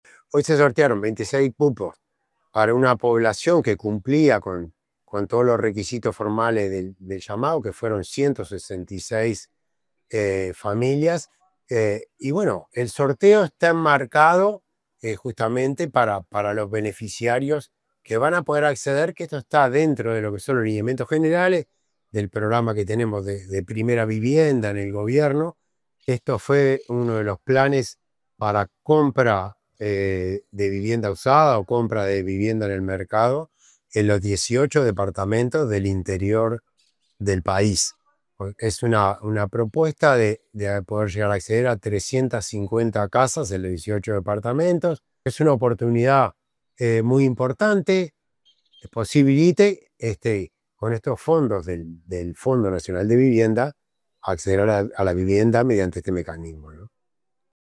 Declaraciones del director nacional de Vivienda, Milton Machado